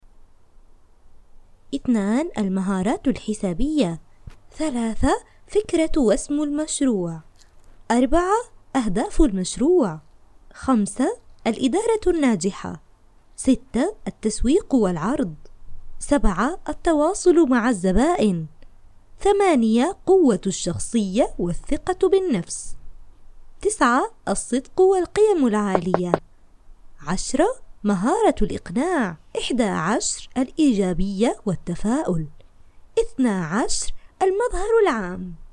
女阿07 阿拉伯语女声 低沉|激情激昂|大气浑厚磁性|沉稳|娓娓道来|科技感|积极向上|时尚活力|神秘性感|调性走心|亲切甜美|感人煽情|素人